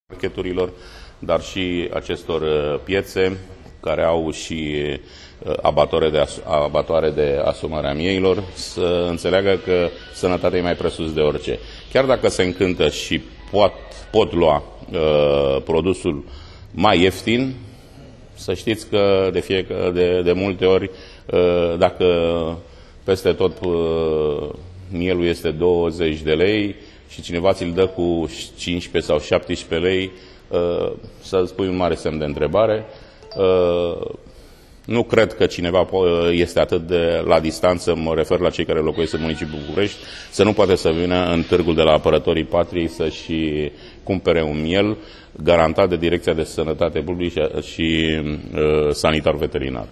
Cu prilejul deschiderii Târgului de Miei, Primarul Sectorului 4, Cristian Popescu-Piedone, i-a îndemnat pe toți bucureștenii să cumpere carne de miel doar din surse verificate și să nu sacrifice siguranța de dragul unor prețuri mai mici.